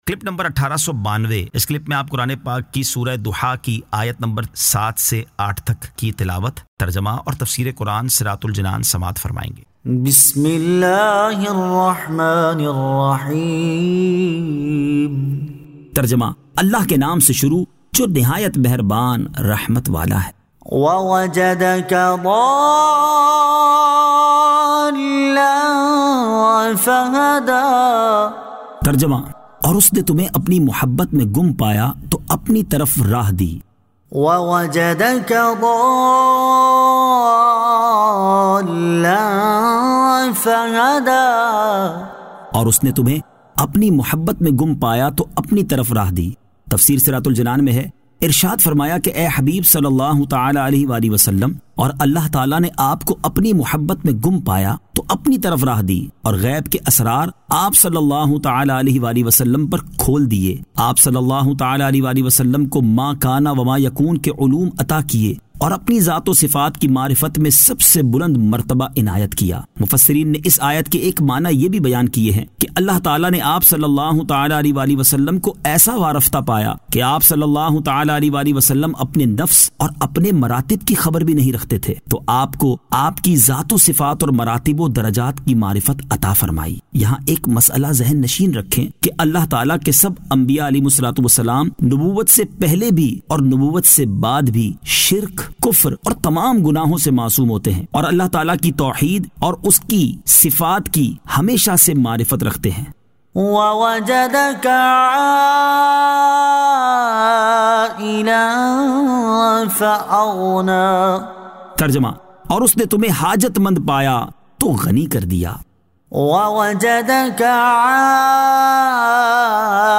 Surah Ad-Duhaa 07 To 08 Tilawat , Tarjama , Tafseer